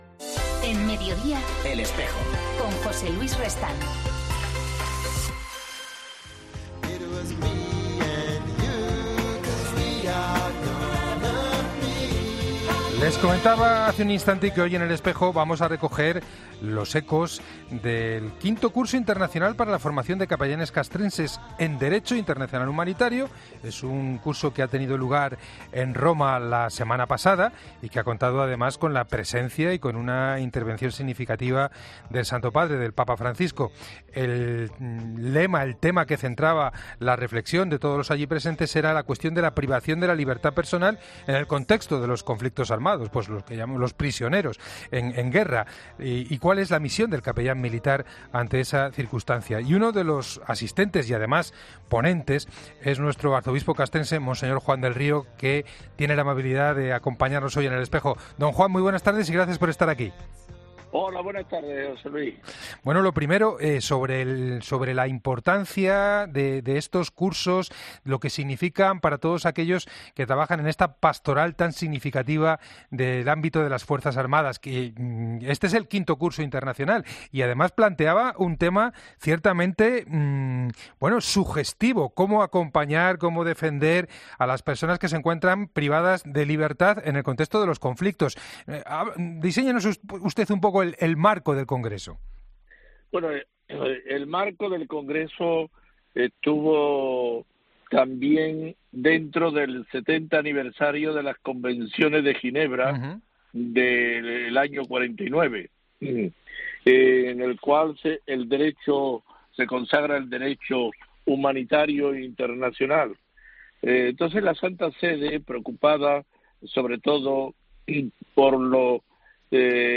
El Arzobispo castrense Mons. Juan del Río explica en 'El Espejo' el contenido del Curso Internacional para la formación de Capellanes Castrenses.